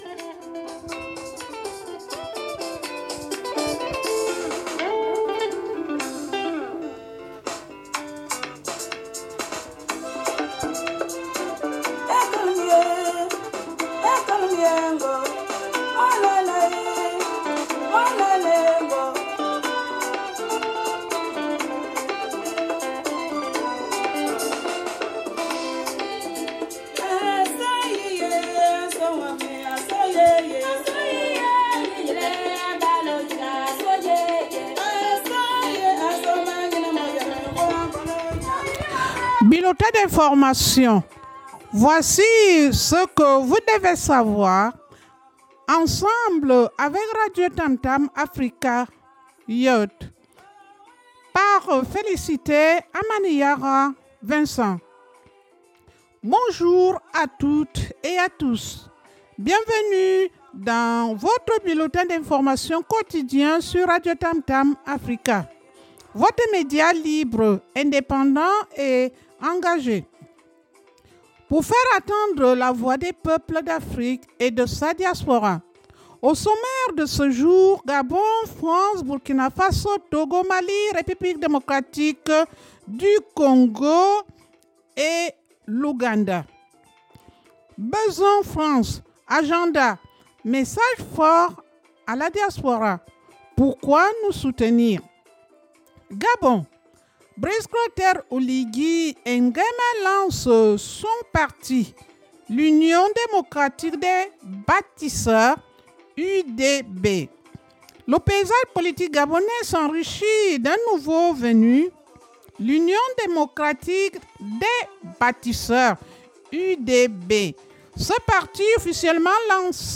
Radio TAMTAM AFRICA BULLETIN D’INFORMATION BULLETIN D'INFORMATION 06 juillet 2025